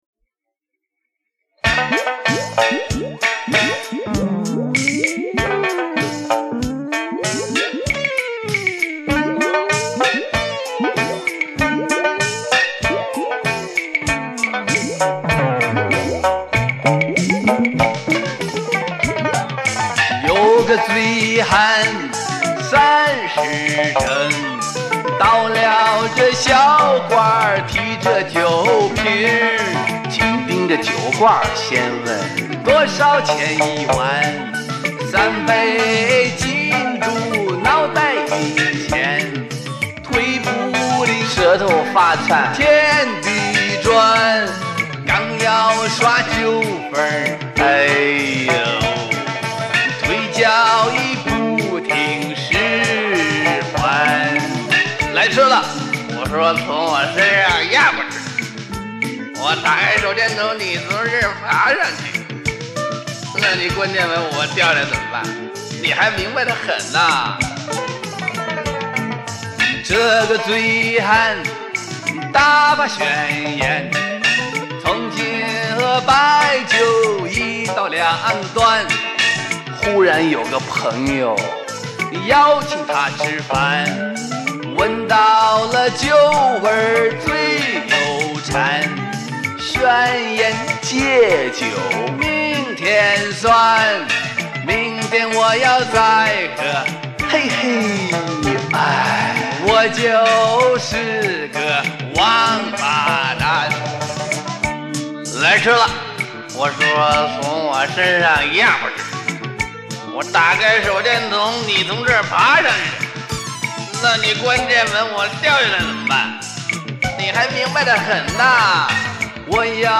[18/5/2010]幽默歌曲 《醉汉宣言》（192K MP3) 激动社区，陪你一起慢慢变老！